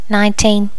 Normalize all wav files to the same volume level.